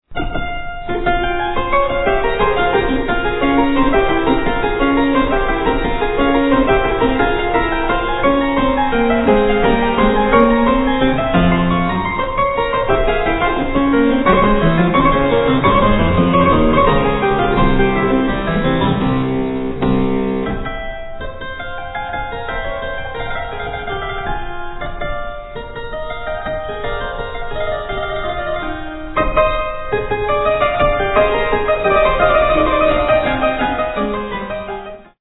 harpsichordist
Sonata for keyboard in F minor, K. 183 (L. 473) - 4:23